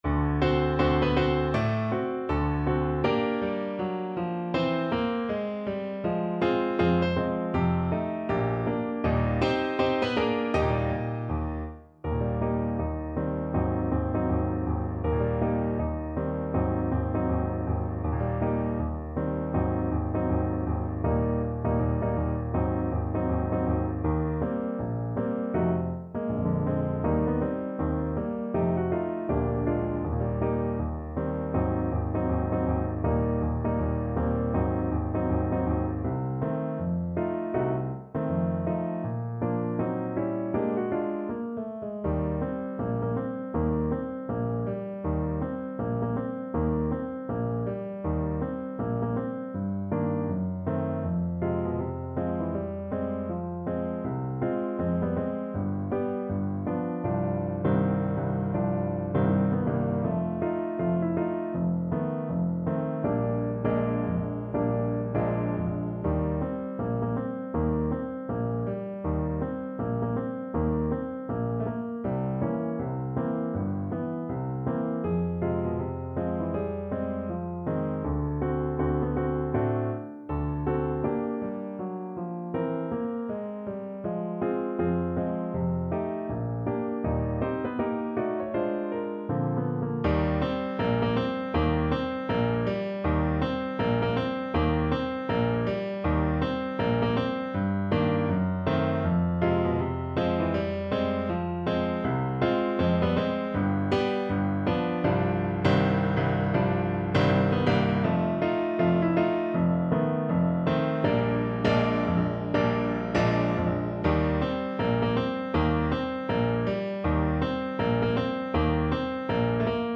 ~ = 160 Moderato
Jazz (View more Jazz Saxophone Music)